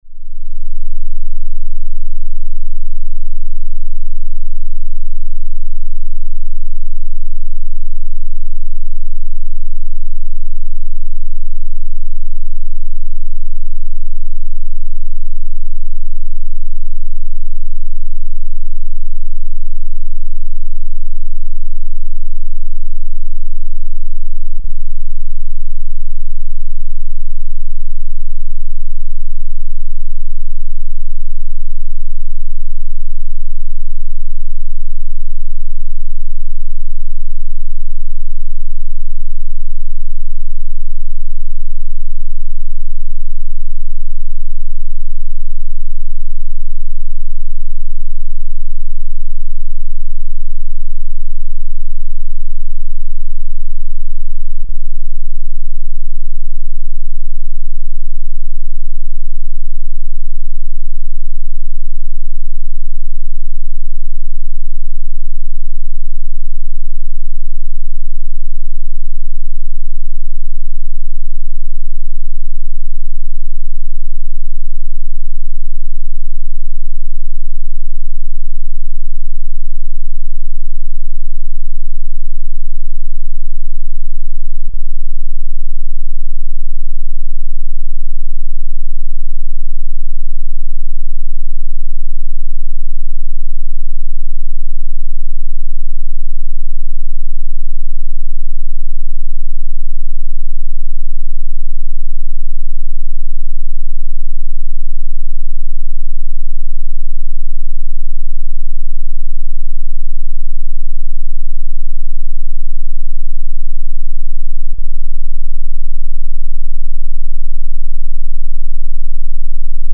На этой странице собраны записи инфразвука — низкочастотных колебаний, находящихся за пределами обычного человеческого восприятия.
2. Инфразвук на частоте 12 герц